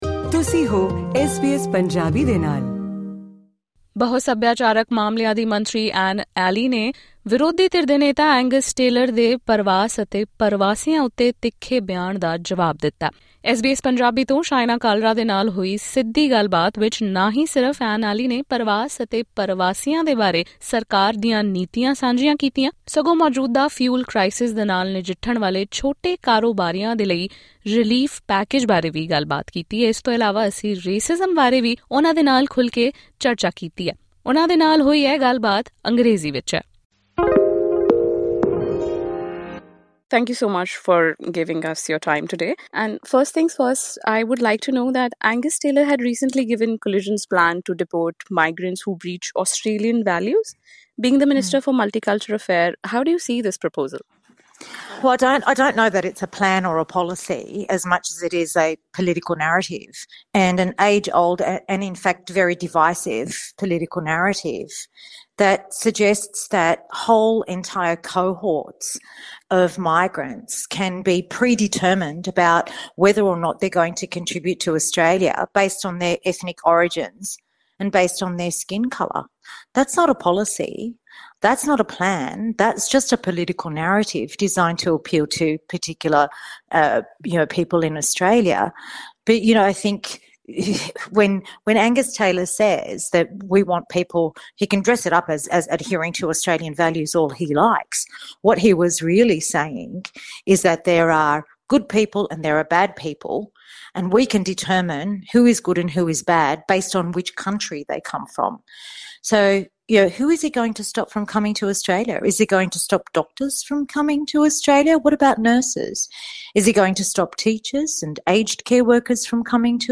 In an exclusive chat with SBS Punjabi, Dr Anee Aly talks about relief for small businesses amid ongoing fuel shortage Credit: AAP Images
In her exclusive chat with SBS Punjabi, the minister also talks about the proposed National Anti-Racism Framework, government support for small business owners amid rising fuel costs and much more.